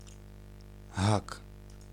Ääntäminen
IPA : [hʊk] US : IPA : [hʊk]